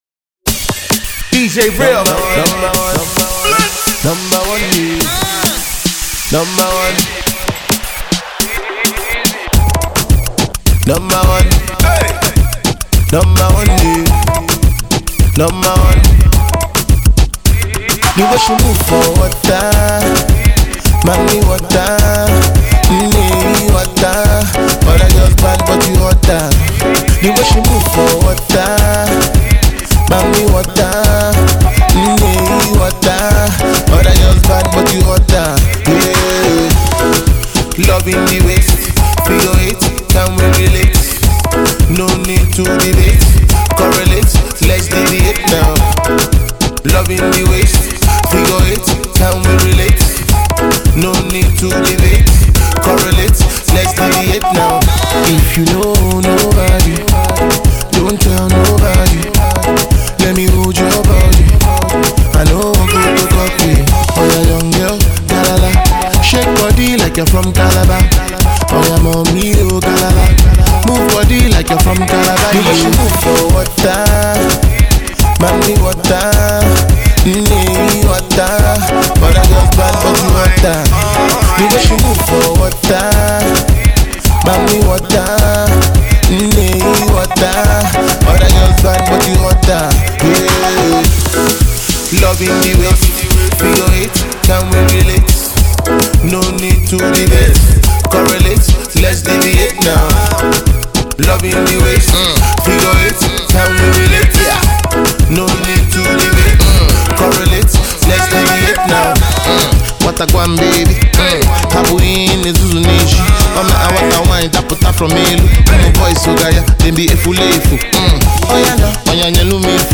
Its a Good Pop Song